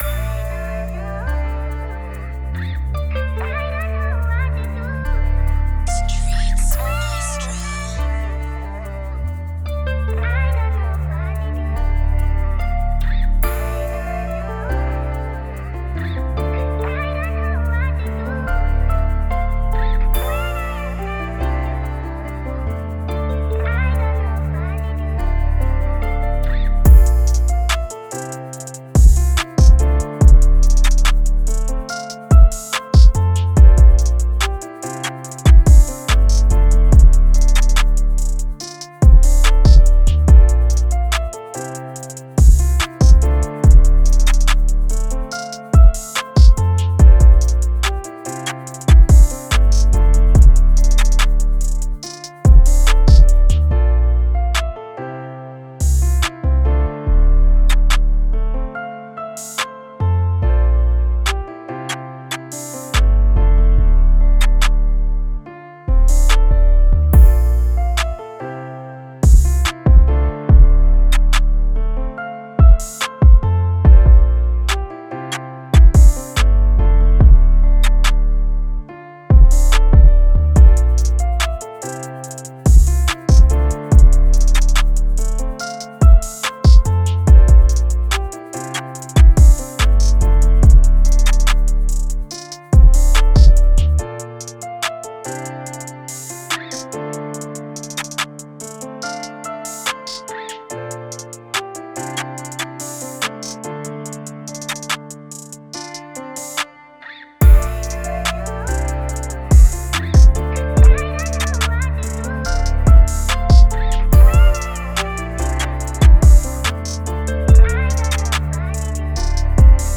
Moods: emotional, laid back, pain,
Genre: Rap
Tempo: 143